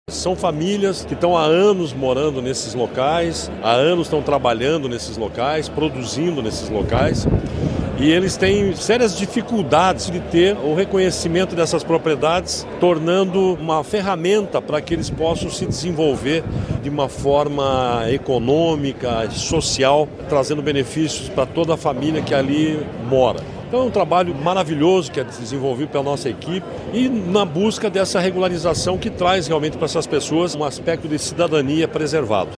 Sonora do diretor-presidente do IAT, Everton Souza, sobre a regularização de 61 propriedades rurais em Boa Ventura de São Roque